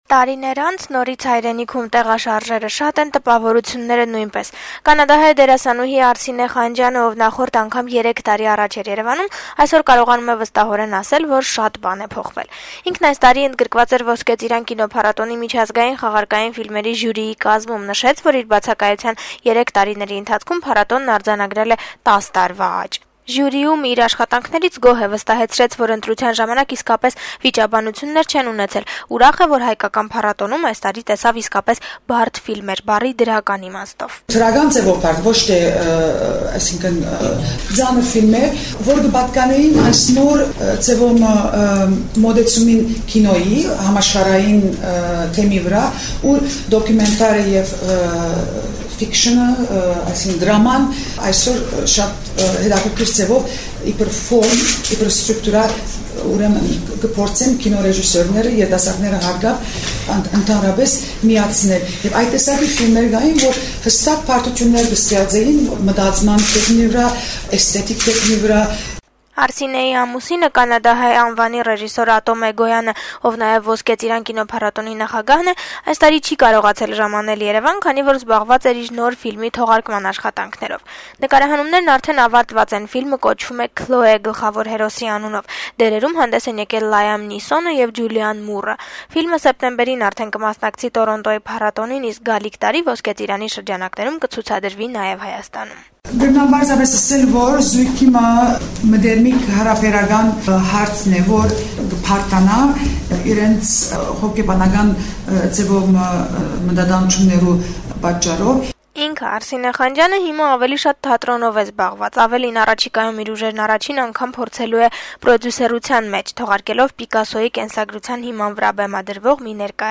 Արսինե Խանջյանի ասուլիսը